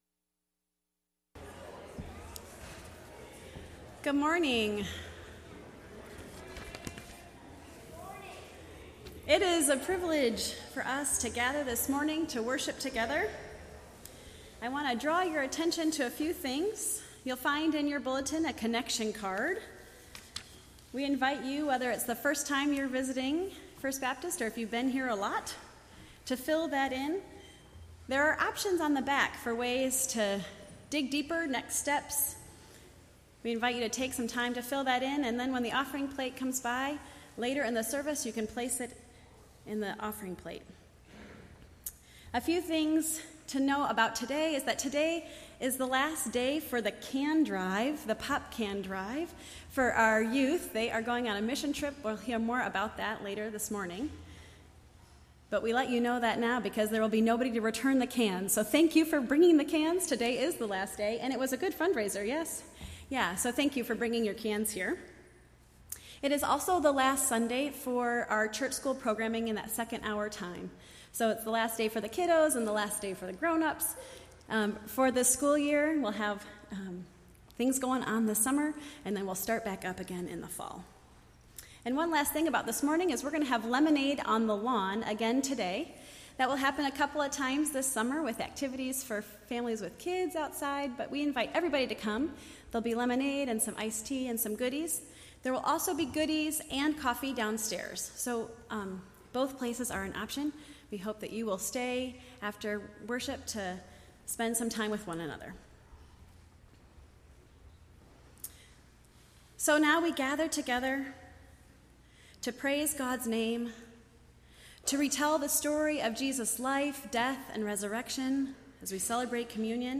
Entire June 11th Service